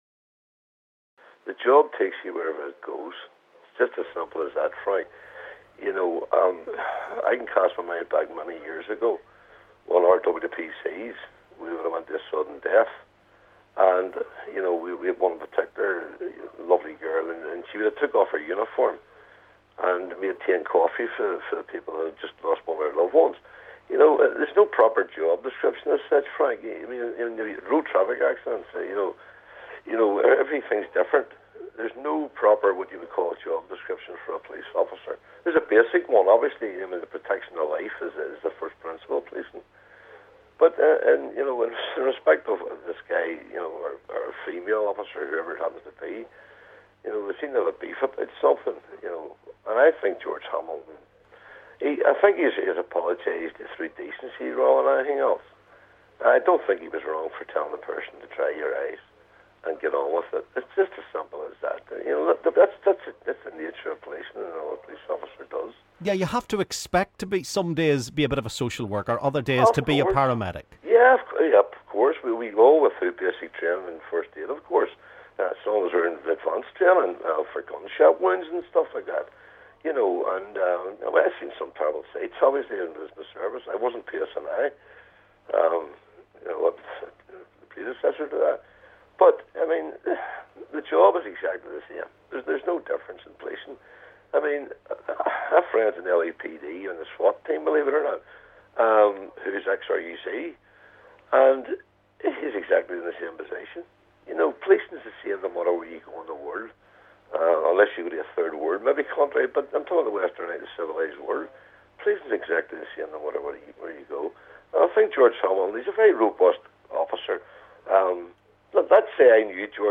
LISTEN: A former police officer gives his reaction to the Chief Constable's Twitter spat